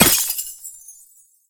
ice_spell_impact_shatter_03.wav